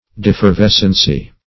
\De`fer*ves"cency\, n. [L. defervescere to grow cool.]